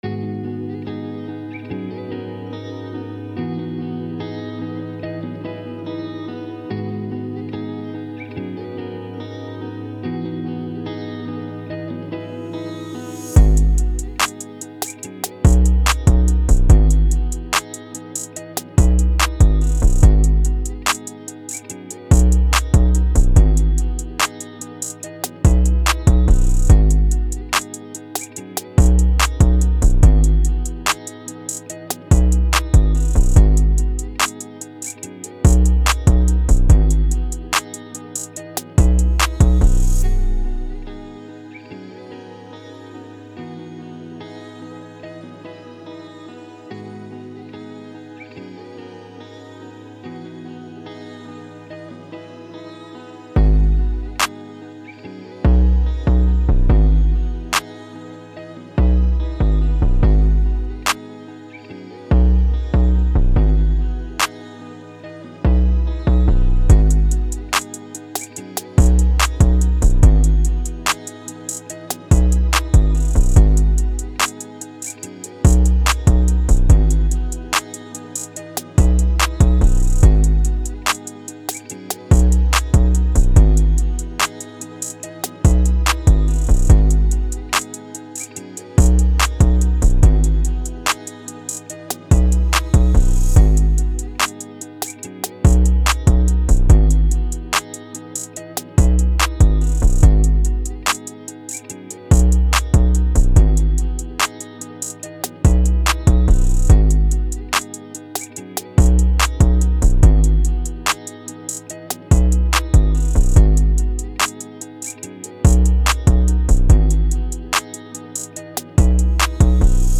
ملو و آروم: